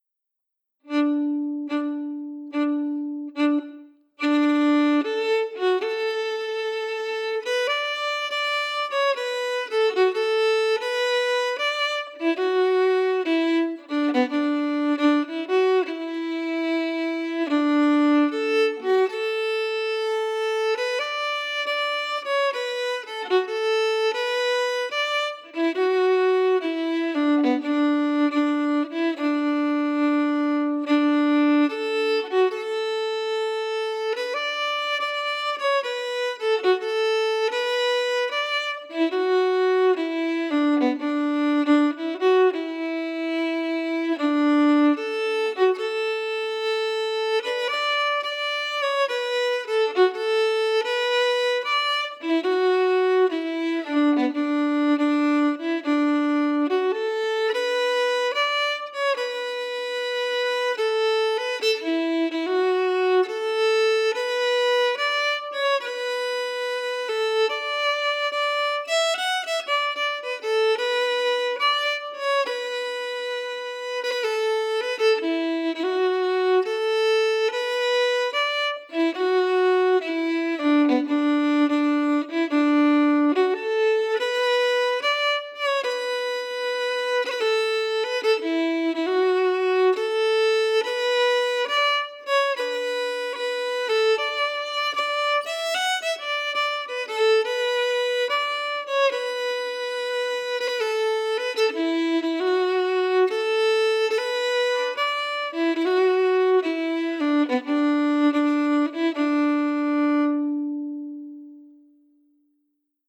Key: D
Form: Strathspey
Slow for learning